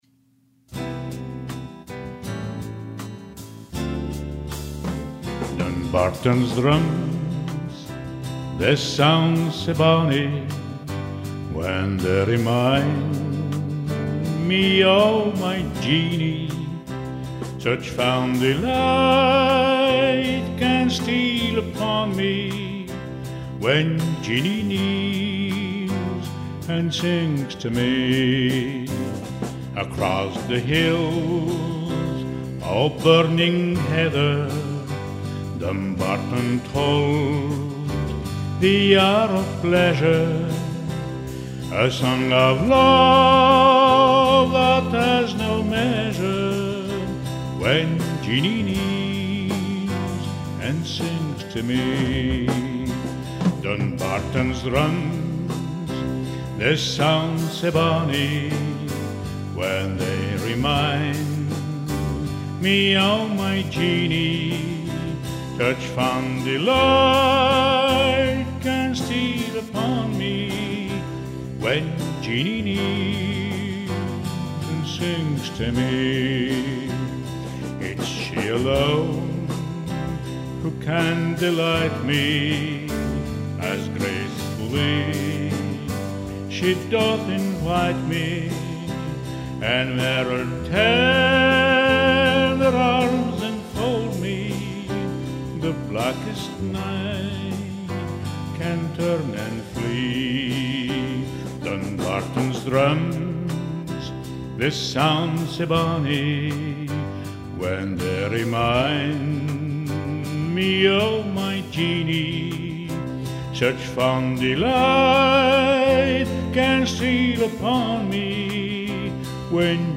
chanson: Dumbarton's drums